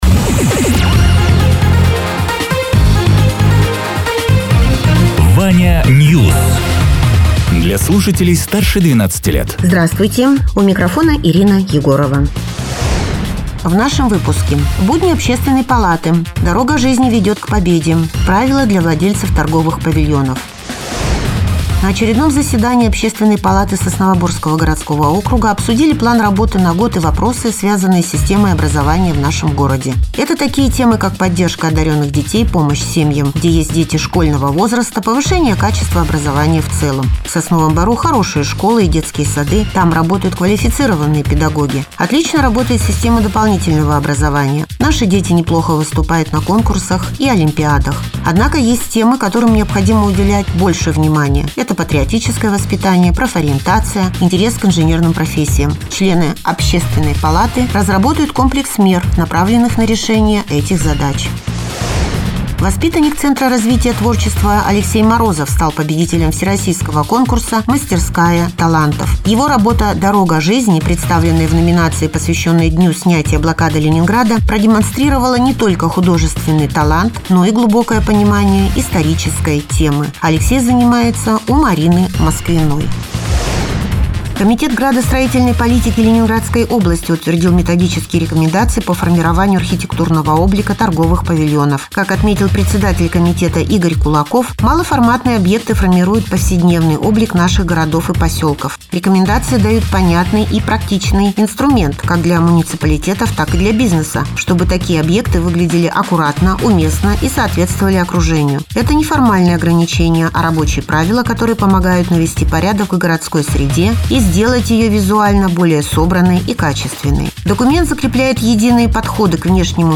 Радио ТЕРА 04.02.2026_08.00_Новости_Соснового_Бора